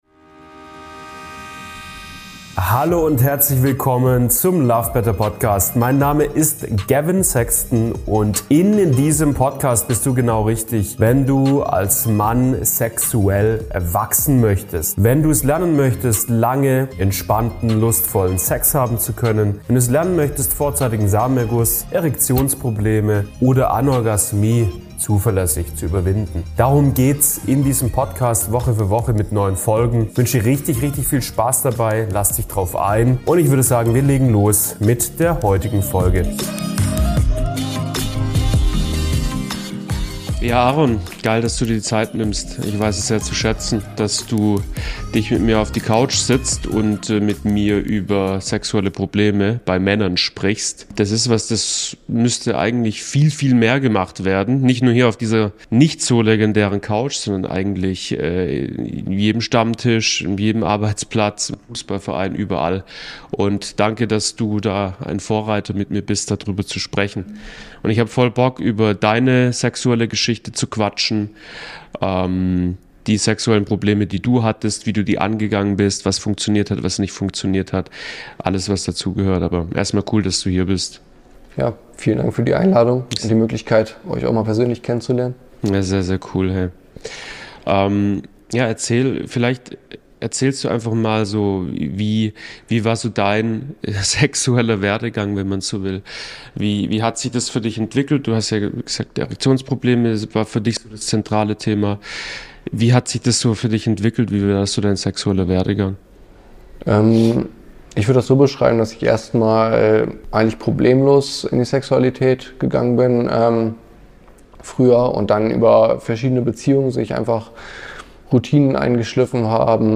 Schlaffer Penis: Wie er das Problem gelöst hat - Interview